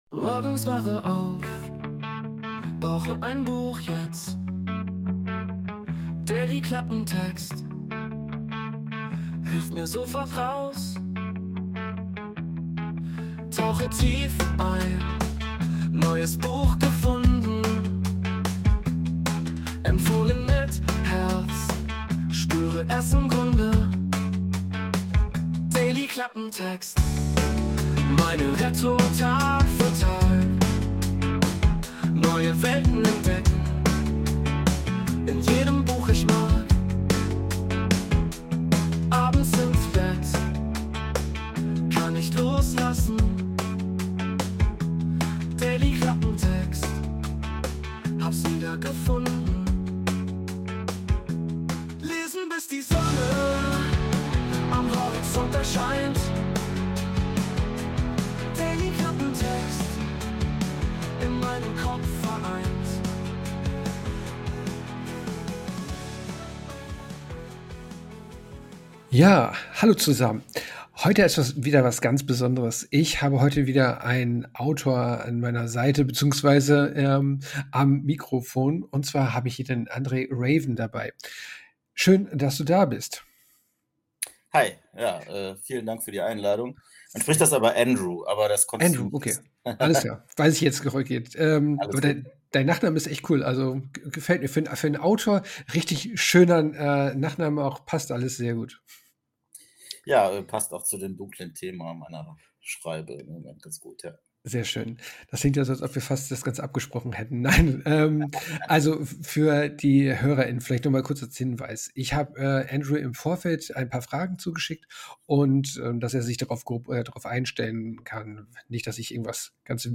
Es ist ein emotionales, persönliches und inspirierendes Gespräch geworden, das nicht nur Fantasy-Fans begeistern dürfte.
Intro: Wurde mit der KI Suno erstellt.